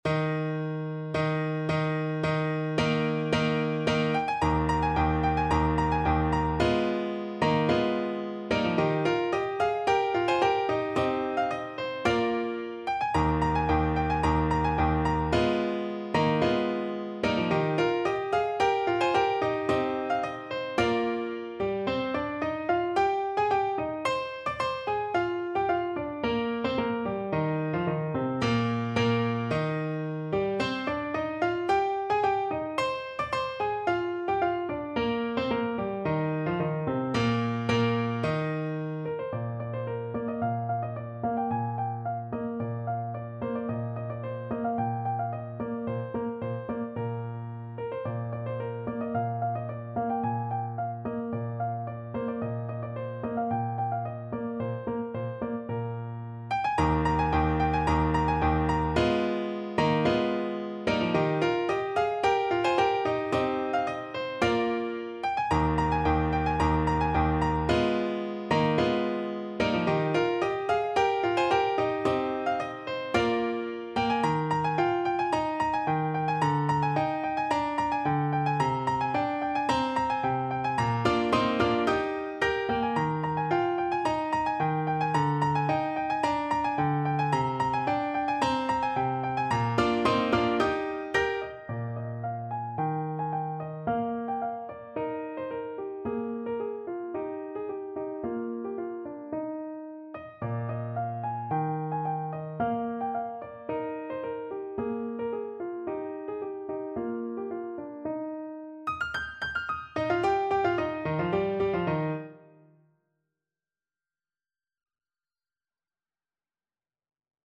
= 110 Allegro di molto (View more music marked Allegro)
Classical (View more Classical Saxophone Music)